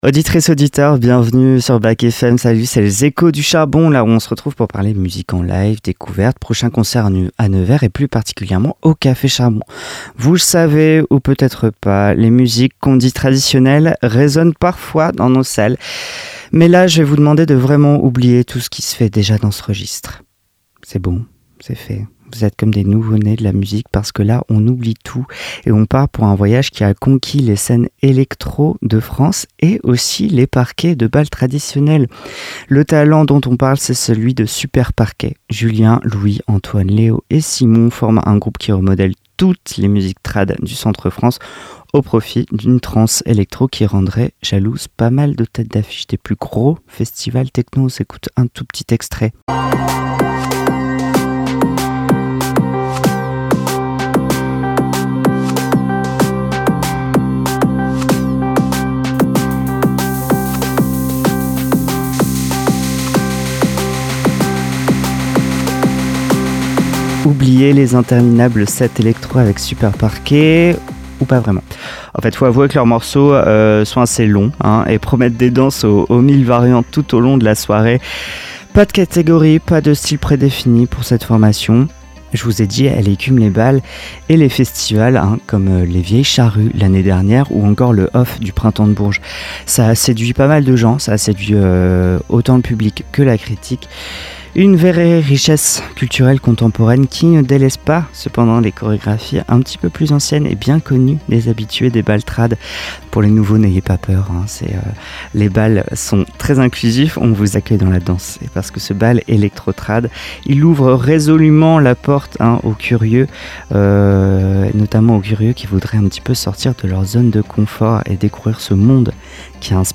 Au programme, des interviews, la présentation des différentes actions menées au sein de la structures, des découvertes et bien sûr de la musique.